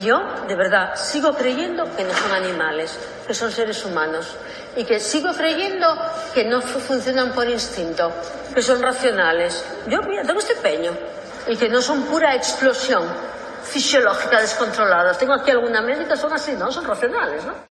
En un acto de partido, la socialista Ana González ha dicho que "sigo creyendo que los hombres no funcionan por instintos, que no son pura explosión fisiológica descontrolada"
Concretamente, por la alcaldesa, la socialista Ana González, que, en un acto de partido, ha hablado de los hombres en los siguientes términos: